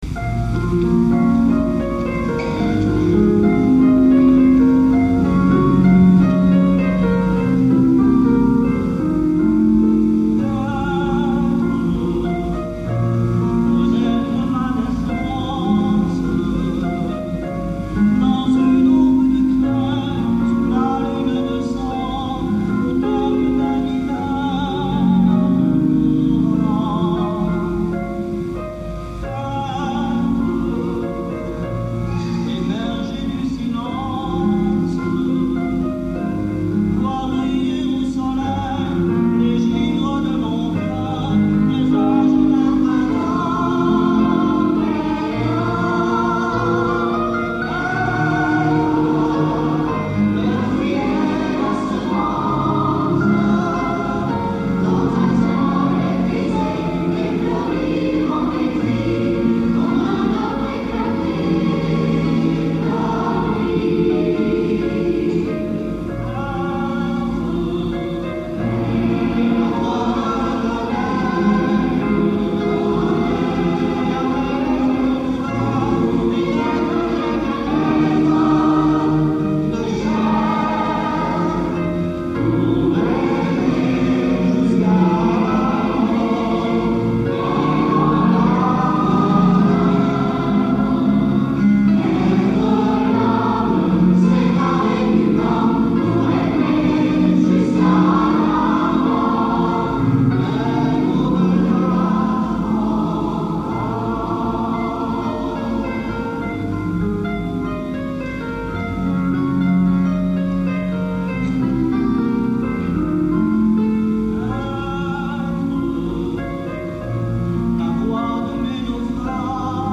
Extraits audio du Concert du 13 mai 1977